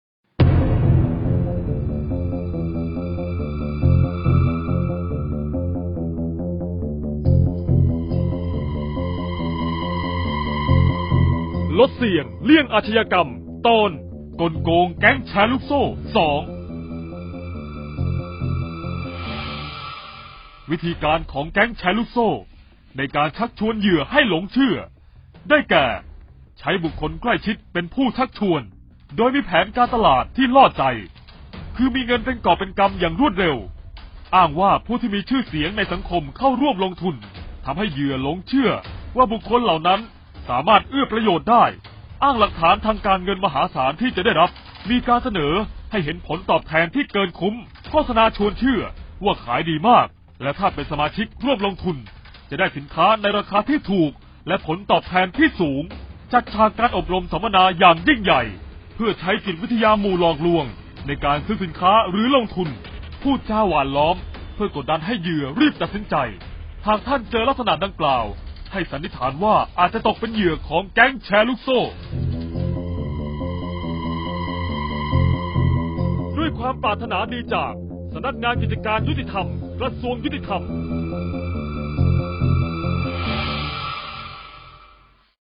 เสียงบรรยาย ลดเสี่ยงเลี่ยงอาชญากรรม 10-แก๊งค์แชร์ลูกโซ่-2